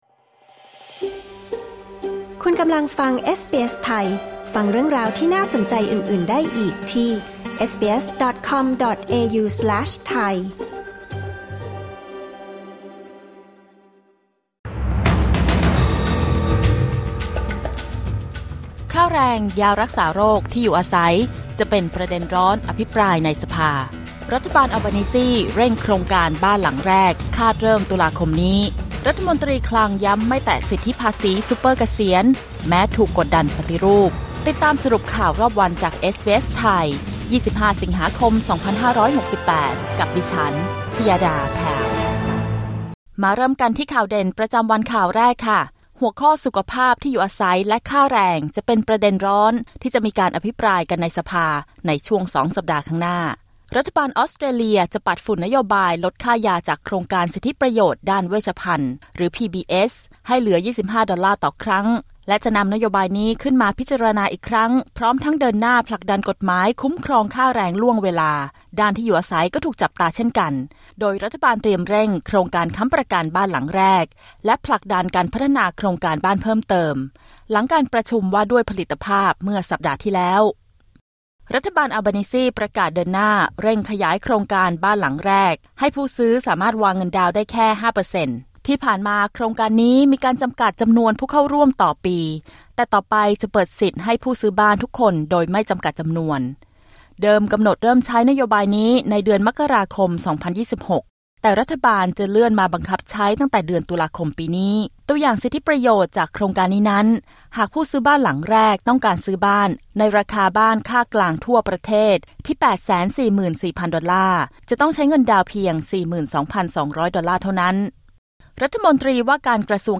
สรุปข่าวรอบสัปดาห์ 25 สิงหาคม 2568